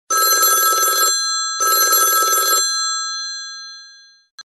classic-telephone_25521.mp3